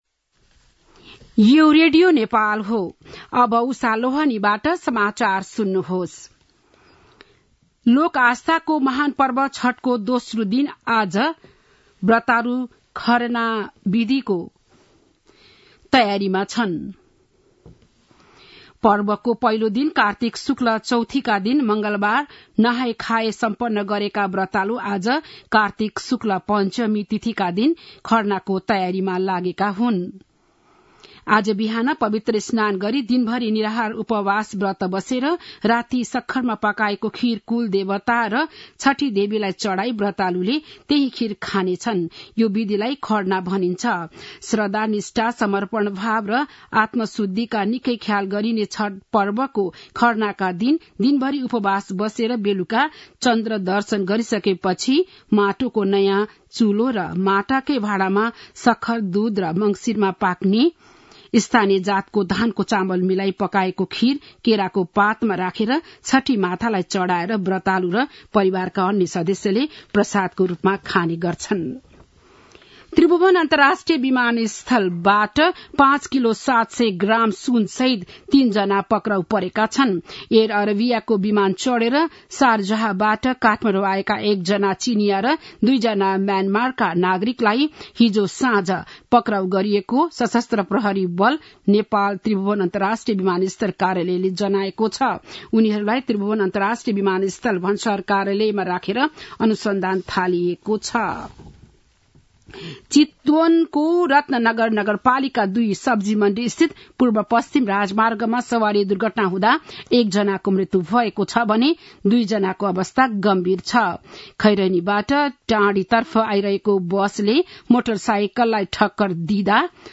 बिहान ११ बजेको नेपाली समाचार : २२ कार्तिक , २०८१
11-am-news-1.mp3